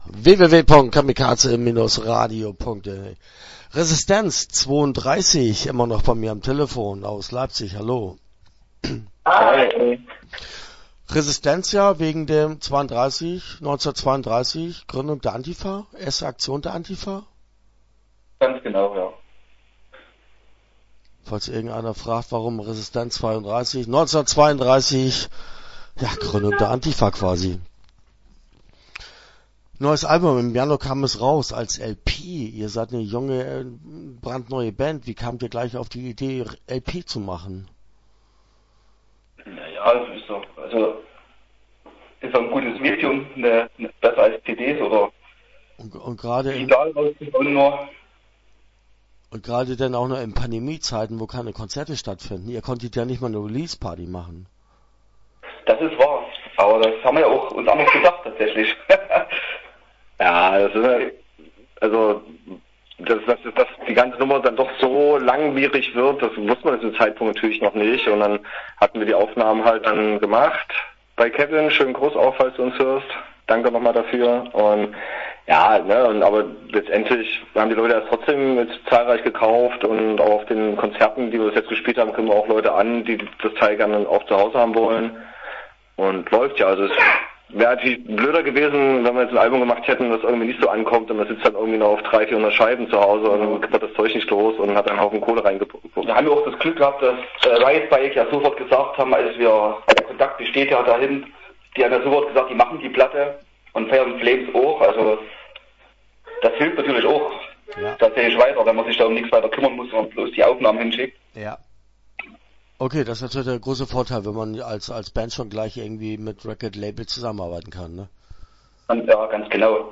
Start » Interviews » Resistenz '32